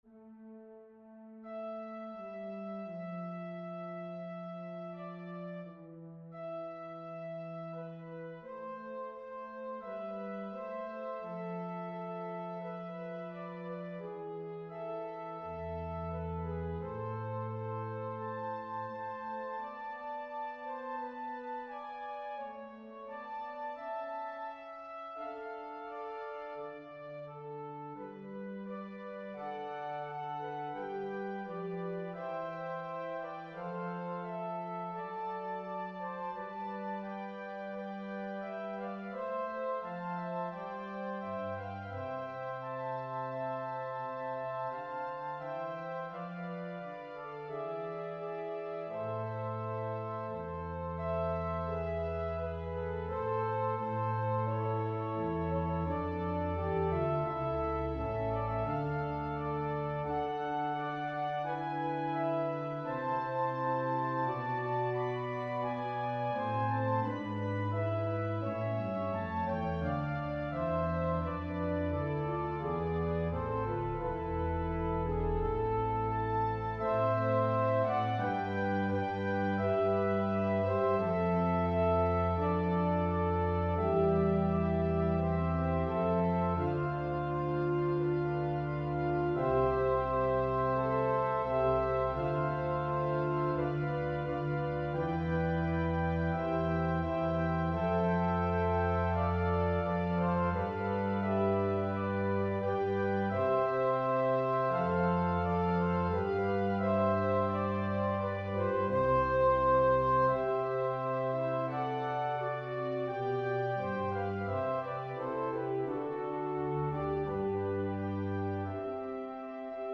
For the Second Decade of prayers of The Chaplet of the Divine Mercy, click the ▶ button to listen to an organ setting of a composition of Ave Verum Corpus by the early French Baroque composer Marc-Antoine Charpentier (1643-1704), or play the music in a New Window